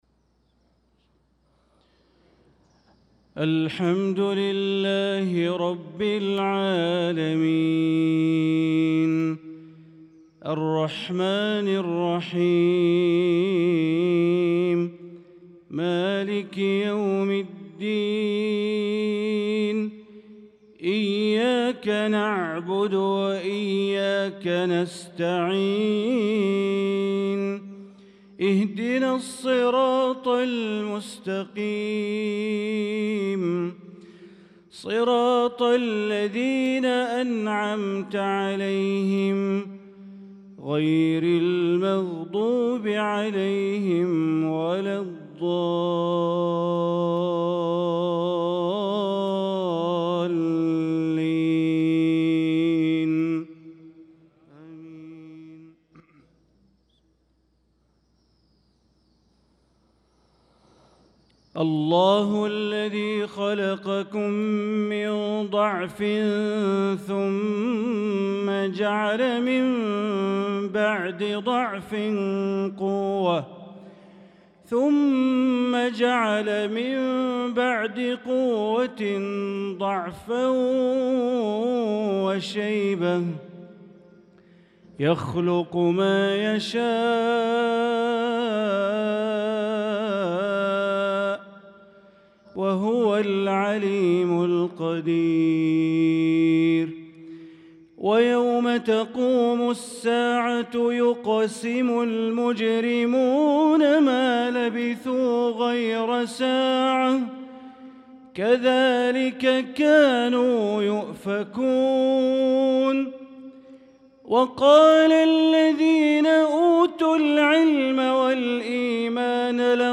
صلاة المغرب للقارئ بندر بليلة 1 ذو القعدة 1445 هـ
تِلَاوَات الْحَرَمَيْن .